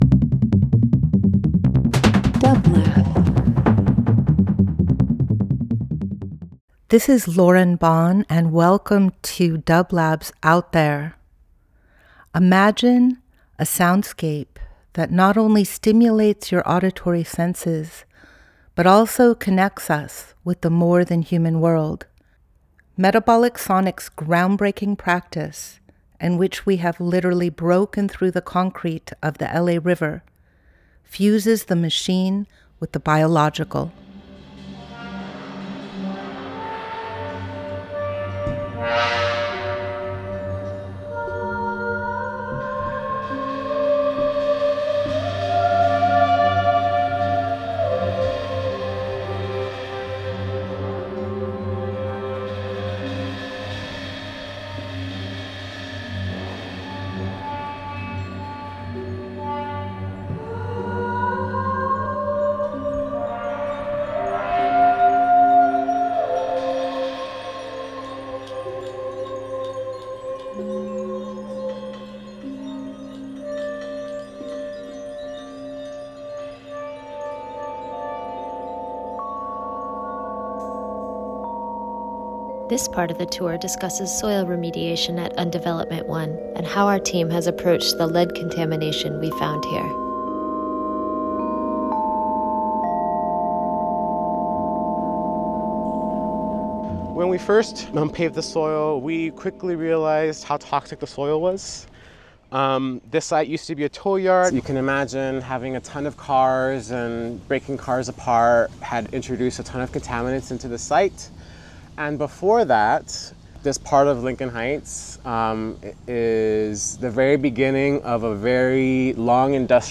Out There ~ a Field Recording Program
Each week we present a long-form field recording that will transport you through the power of sound.
Metabolic Studio Out There ~ a Field Recording Program 08.07.25 Ambient Experimental Field Recording Fourth World Voyage with dublab and Metabolic into new worlds.